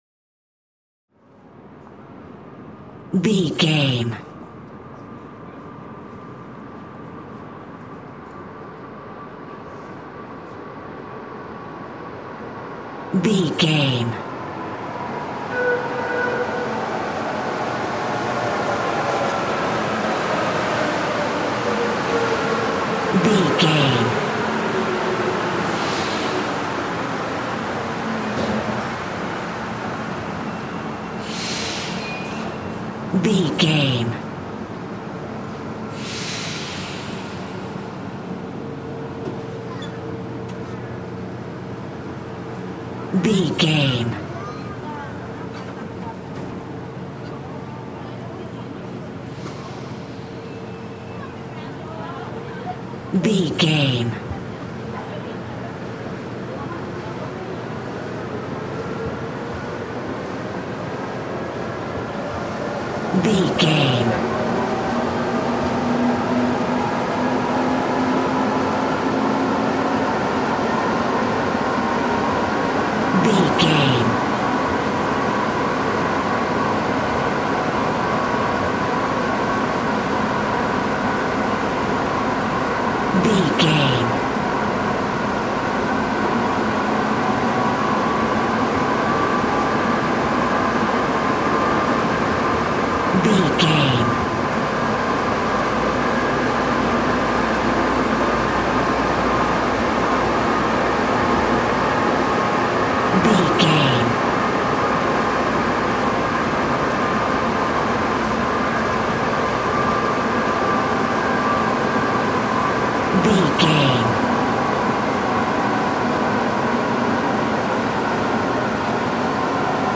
Subway train arrive leave int station wallas
Sound Effects
urban
ambience